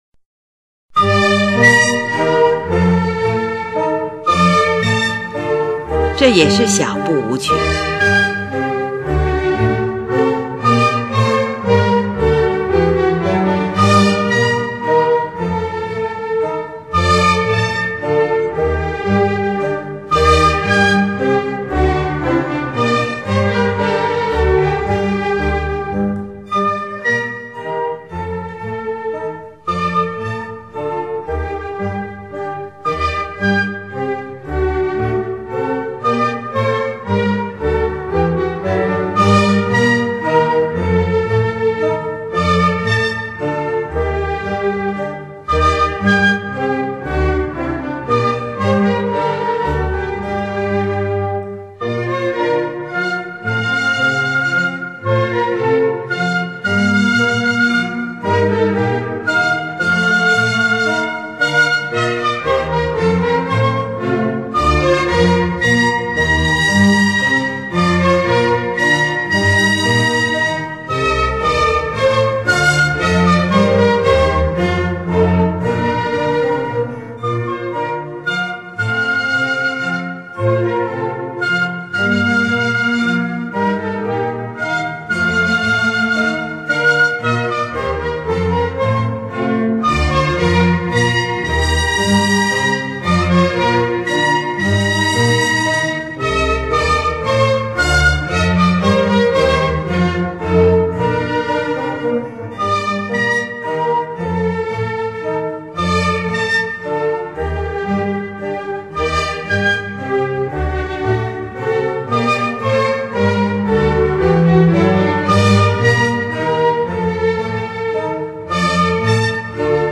19）这是首充满柔情的舞曲，温柔婉转。
是一部管弦乐组曲。
乐器使用了小提琴、低音提琴、日耳曼横笛、法兰西横笛、双簧管、圆号、小号等